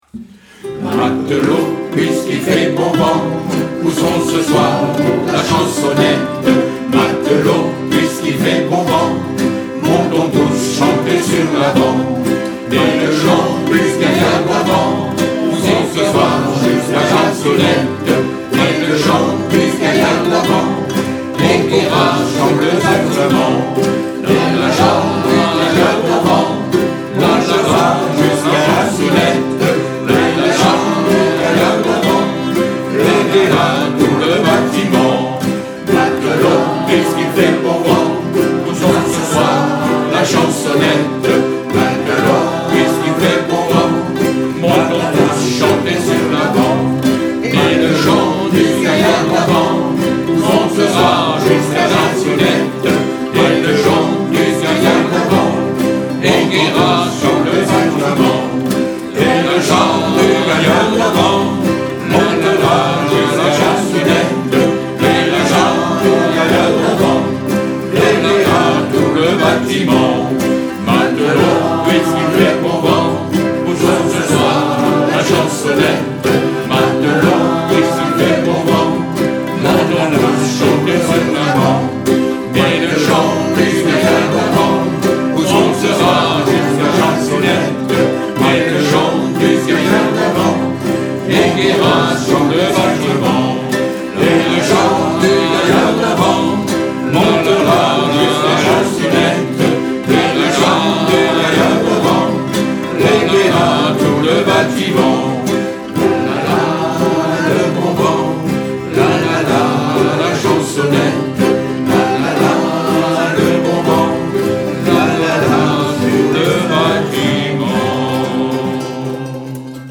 Intro : instrument (2 premières lignes)
4-Matelot-Canon.mp3